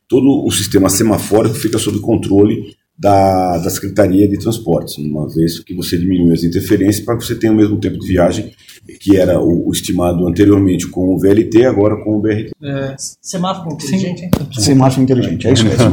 O prefeito de São Caetano do Sul, José Auricchio Júnior disse que a velocidade do BRT poderá ser semelhante à do monotrilho porque haverá um sistema de semáforos inteligentes que privilegie ônibus nos cruzamentos